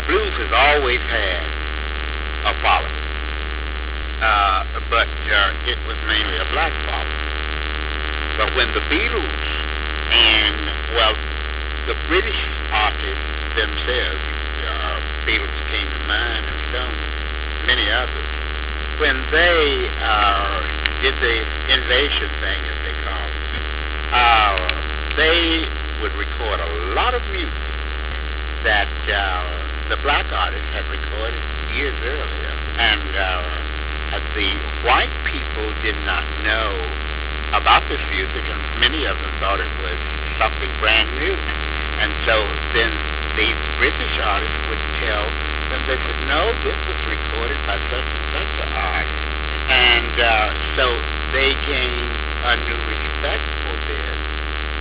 (there is a buzz in this clip)